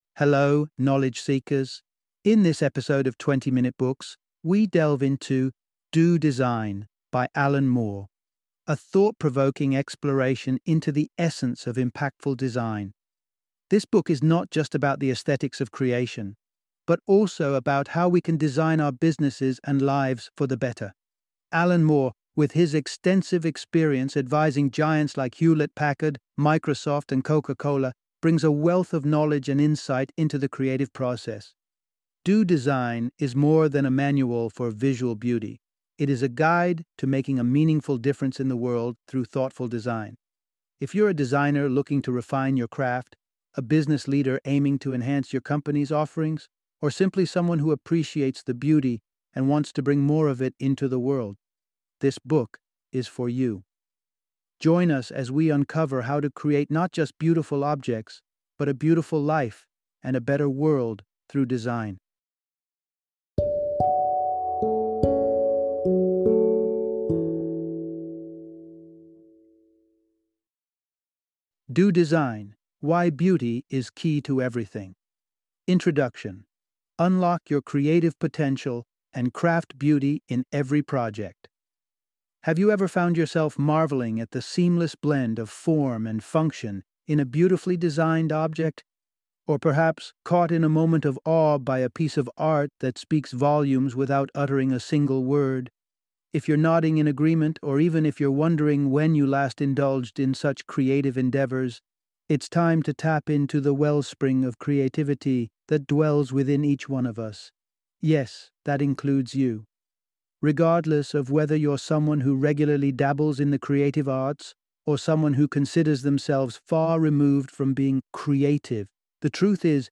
Do Design - Audiobook Summary